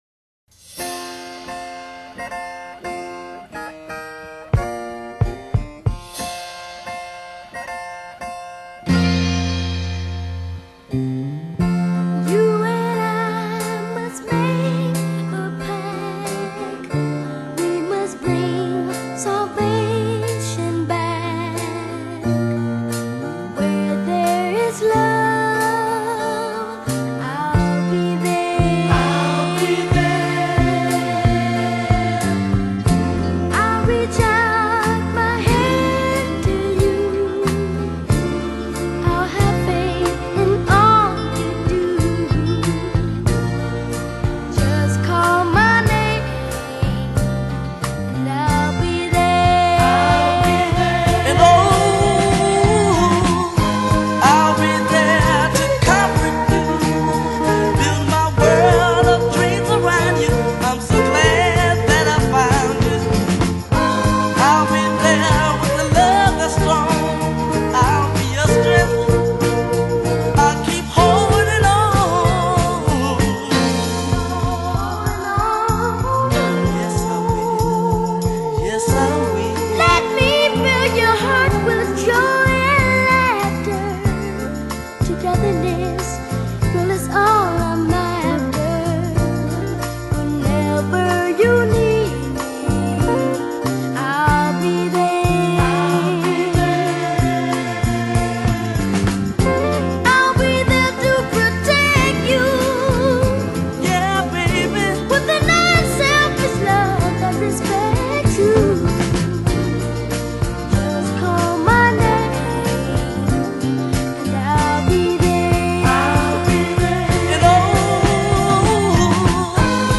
Genre: Disco, Soul